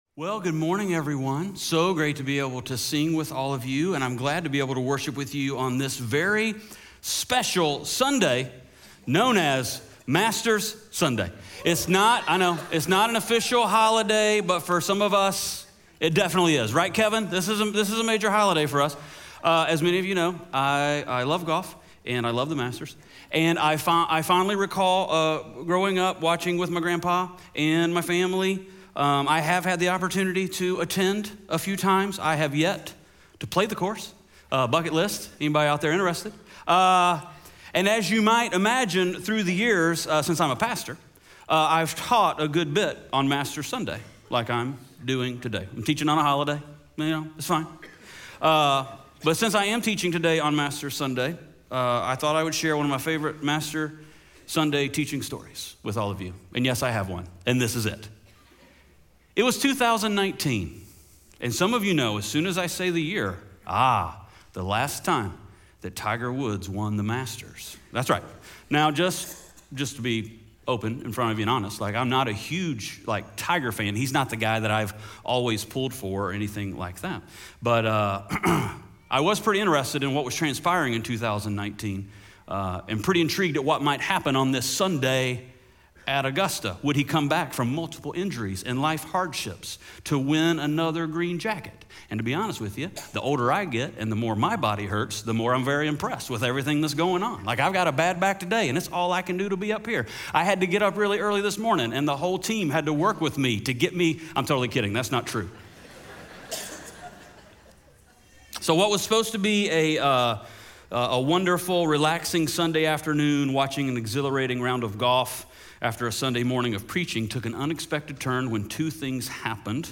2 Samuel 22:1-30 Audio Sermon Notes (PDF) Ask a Question Scripture: 2 Samuel 22:1-30 SERMON SUMMARY 2 Samuel 22 is a song of David.